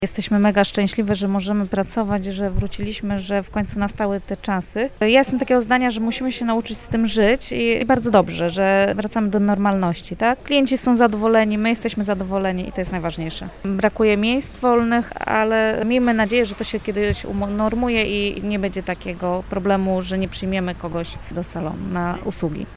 Jedna z fryzjerek podkreśla, że mimo możliwości świadczenia usług, z pandemią trzeba będzie nauczyć się żyć: